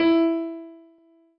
piano-ff-44.wav